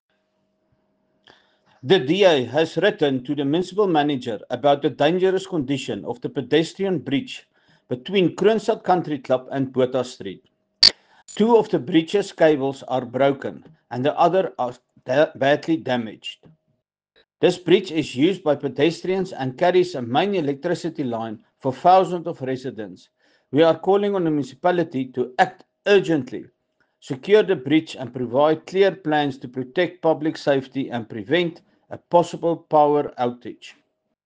Afrikaans soundbites by Cllr Spaski Geldenhuis and Sesotho soundbite by Cllr Palesa Mpele.